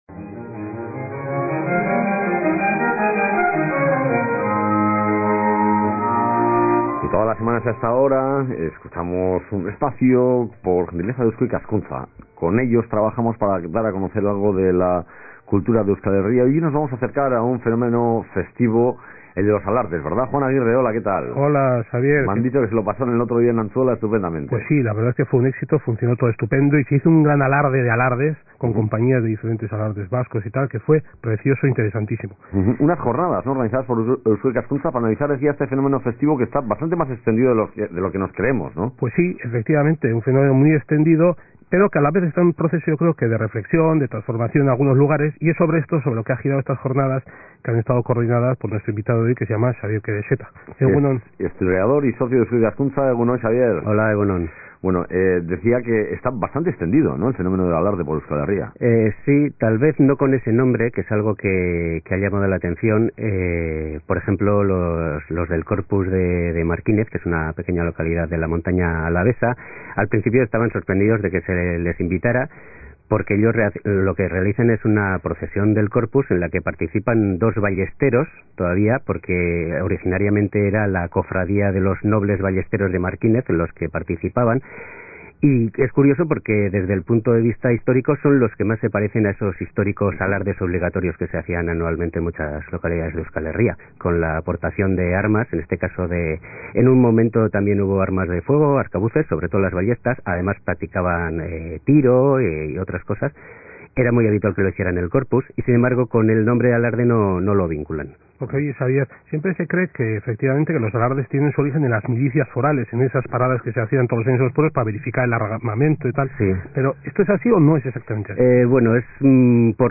en el programa Boulevard de Radio Euskadi